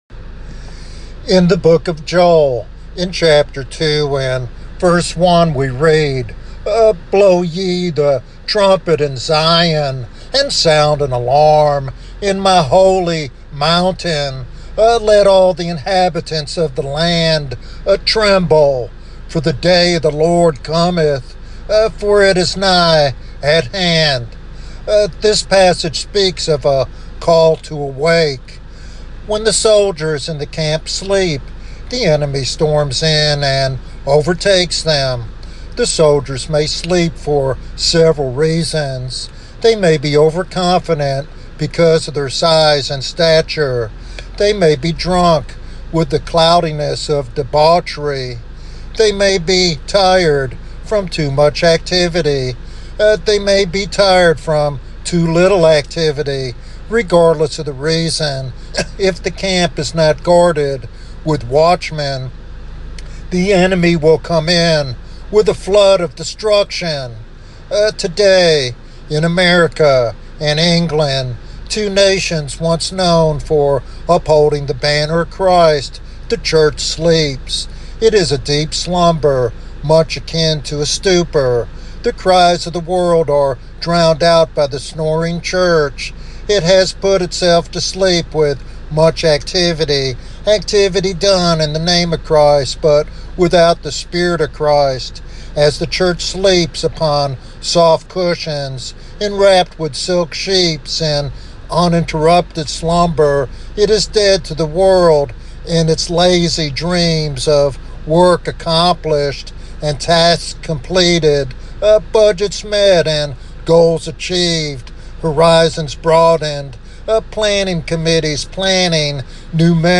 This sermon calls believers to develop a burden for revival, engage in heartfelt prayer, and boldly proclaim the gospel to a world in desperate need.